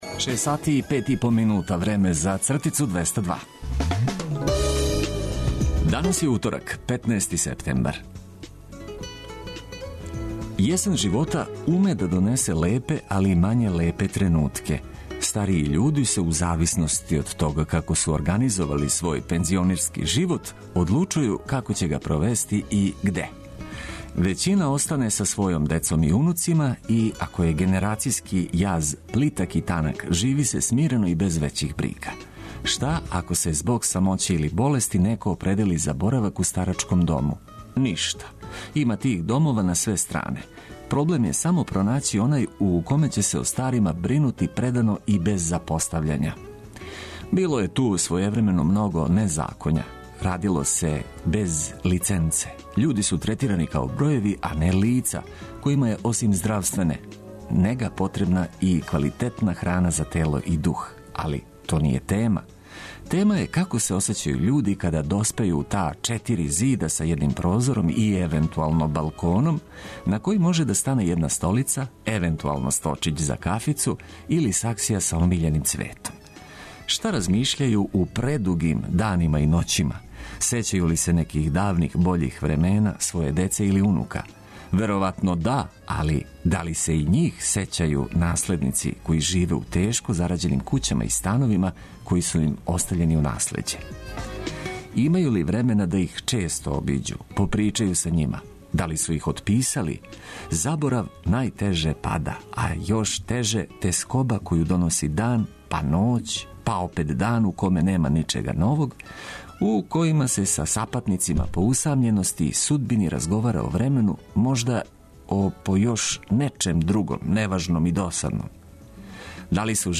Устанак 202 започињемо уз ведру музику и корисне информације.